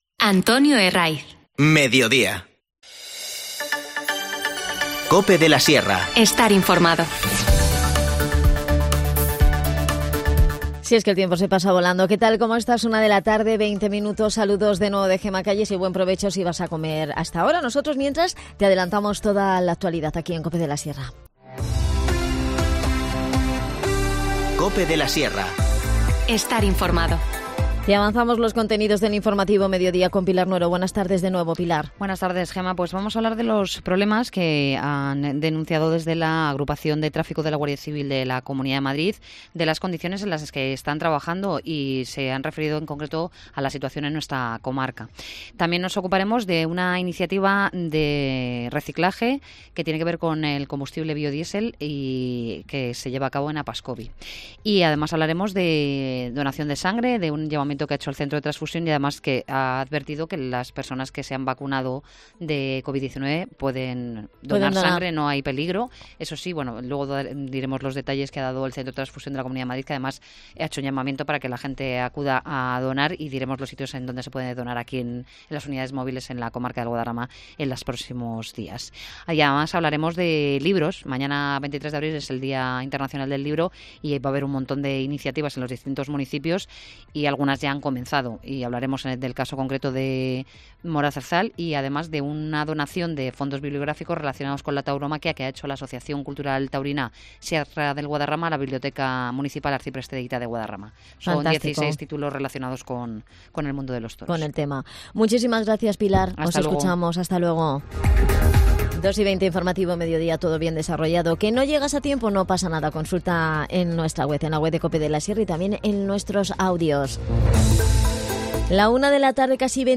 Nos cuenta todos los detalles Pepe Colmenero, concejal de Cultura.